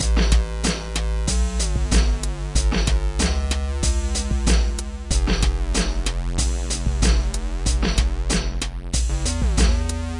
缓慢的沟槽循环
描述：一个缓慢的、欢快的、有节奏感的循环；) 包括鼓、低音和合成器的独立文件。
Tag: 贝斯 寒冷 旋律 hip_hop 器乐 循环 流行 合成器 trip_hop